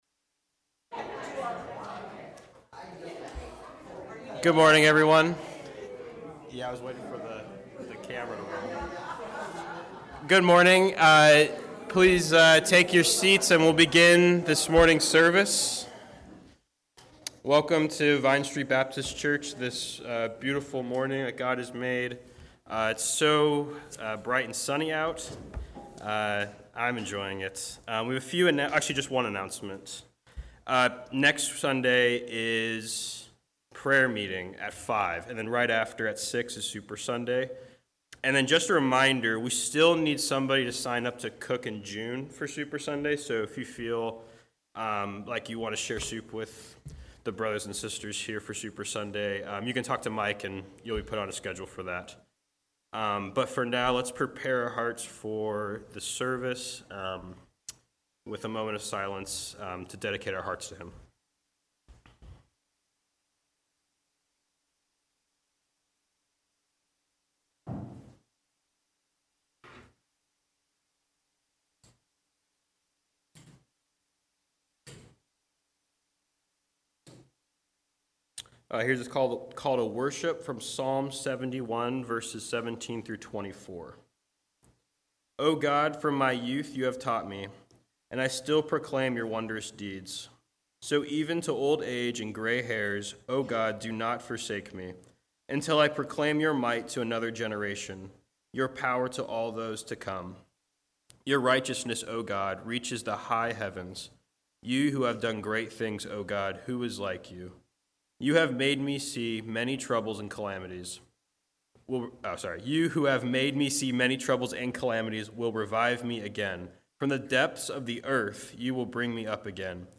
April 24 Worship Audio – Full Service